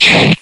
Slash2.ogg